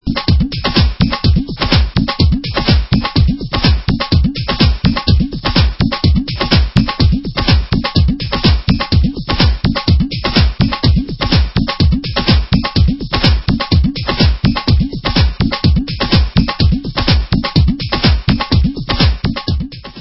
Kick & Kholki